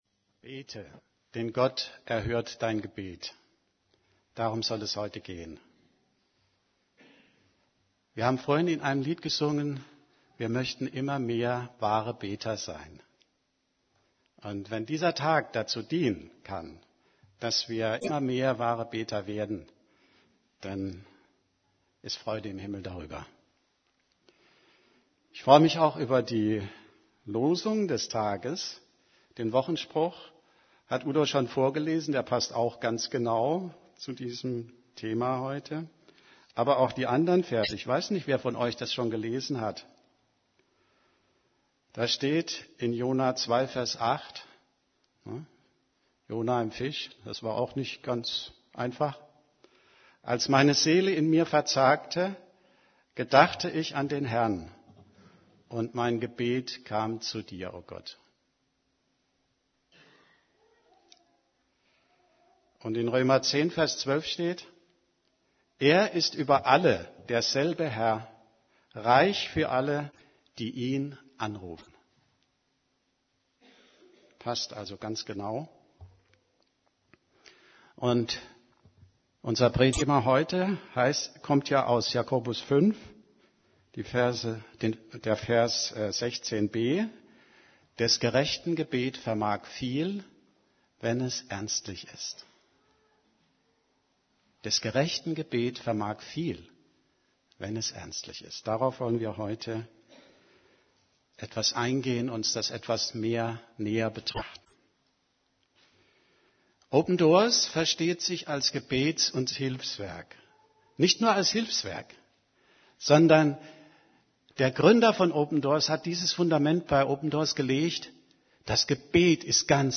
> Übersicht Predigten Fürbitte Predigt vom 27.